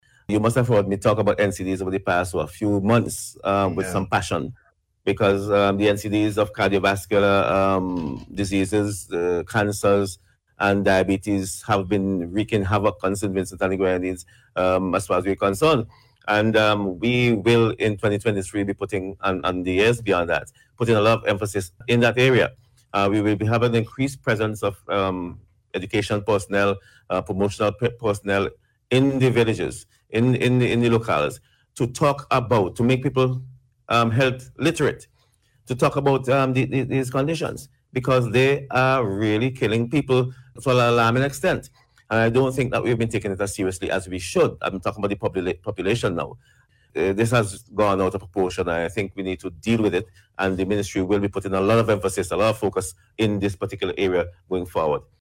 So says Minister of Health, Wellness and the Environment, St. Clair Prince while speaking on Radio yesterday.